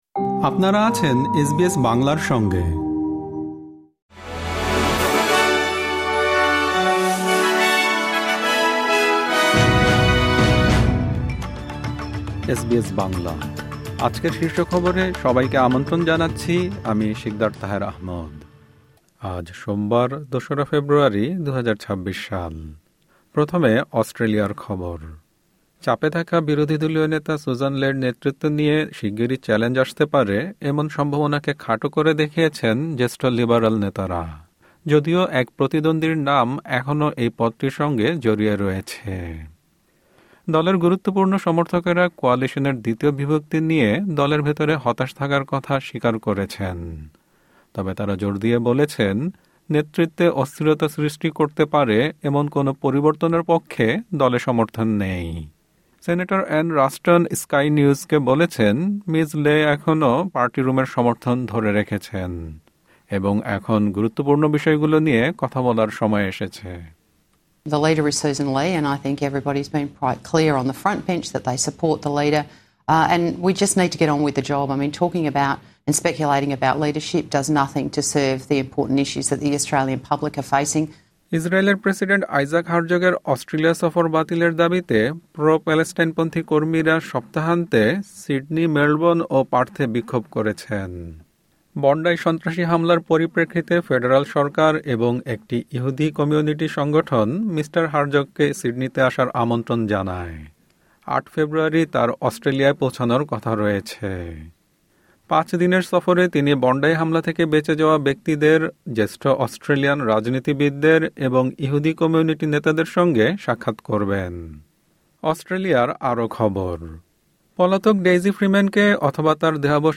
এসবিএস বাংলা শীর্ষ খবর: ইসরায়েলের প্রেসিডেন্টের অস্ট্রেলিয়া সফর বাতিলের দাবিতে বিক্ষোভ